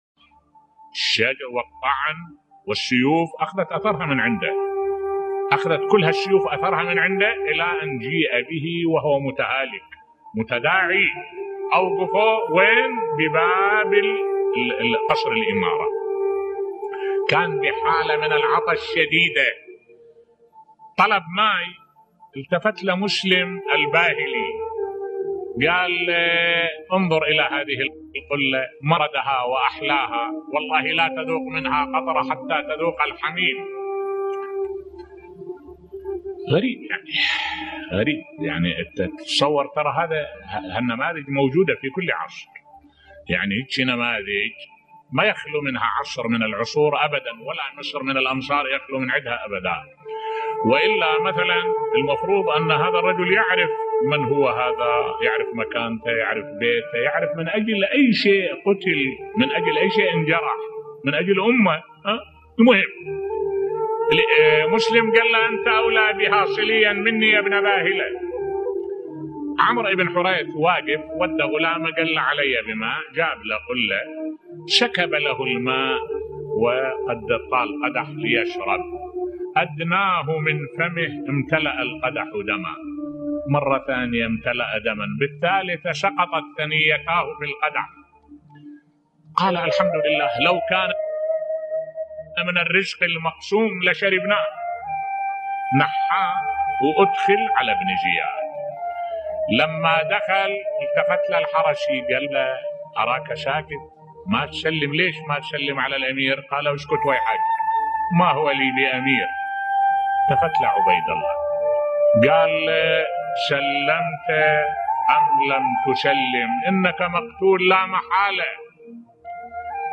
ملف صوتی شهادة سفير الامام الحسين (ع) مسلم بن عقيل بصوت الشيخ الدكتور أحمد الوائلي